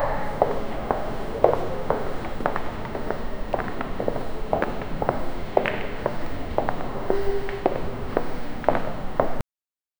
steps in the tunnels under the horror subway station horror absolutely quiet only steps under
steps-in-the-tunnels-unde-4oerfjs7.wav